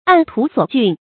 按图索骏 àn tú suǒ jùn
按图索骏发音